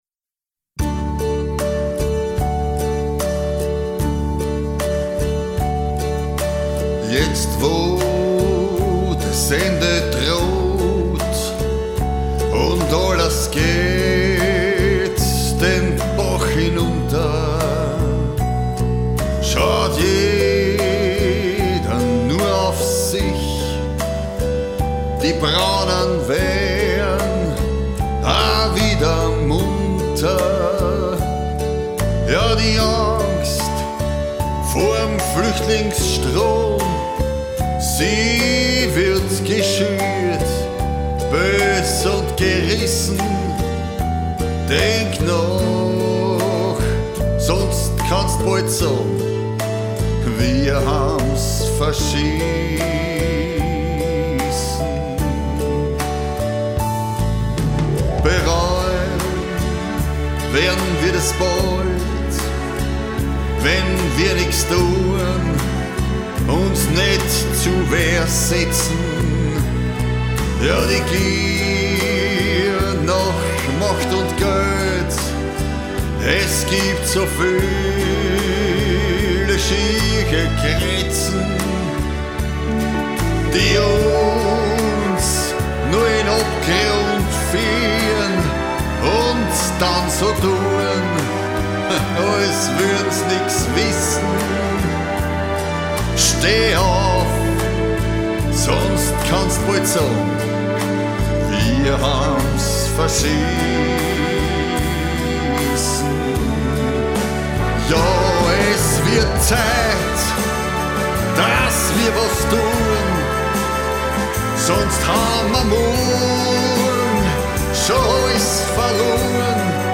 PB and Voice
Cover